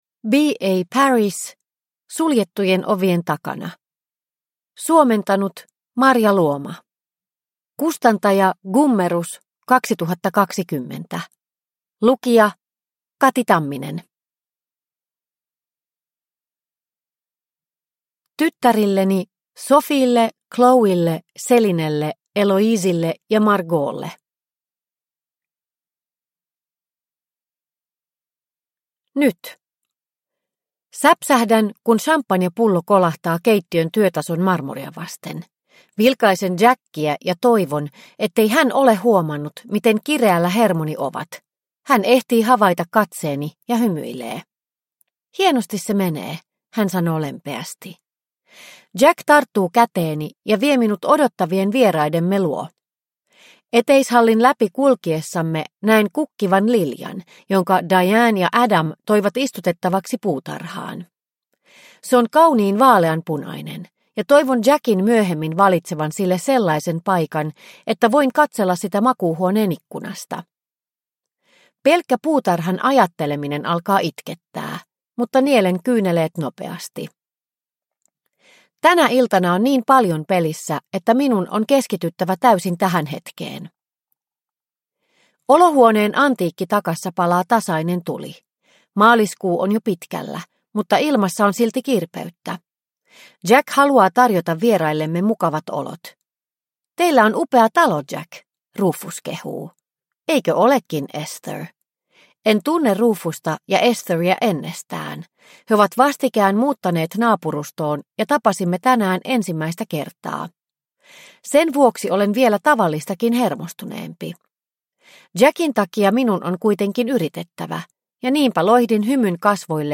Suljettujen ovien takana – Ljudbok – Laddas ner